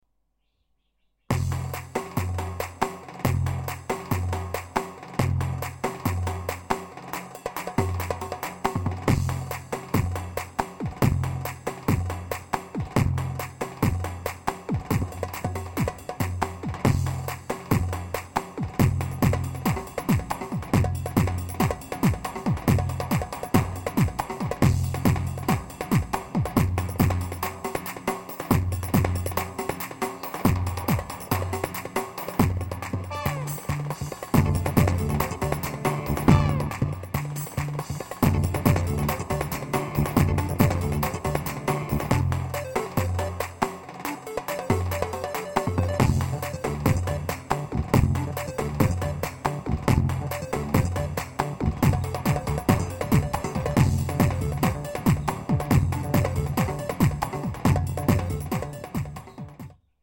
KORG PA 500 TR (yeni roman demo)
Cevap: KORG PA 500 TR (yeni roman demo) yok abi bor müziğin kitide değil pa 80 kiti bu bor müziğin romanlarında böyle canlı davul sesi gelmiyor zaten davul sesleri nasıl ama iyimi